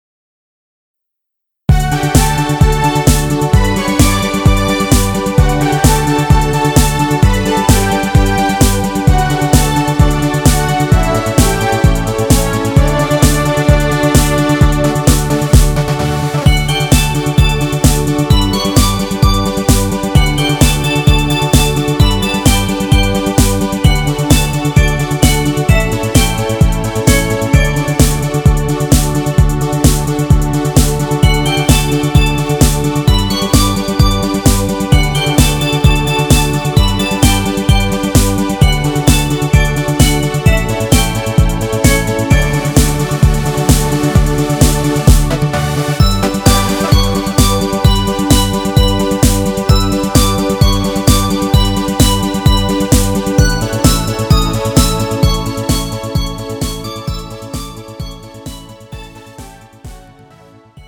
음정 D 키
장르 가요 구분 Pro MR